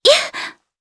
Yuria-Vox_Attack1_jp.wav